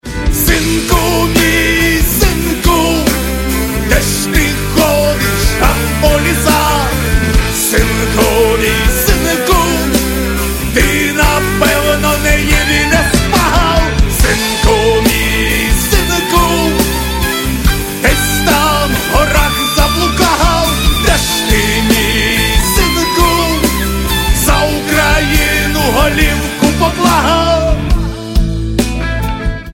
• Качество: 64, Stereo
фолк-рок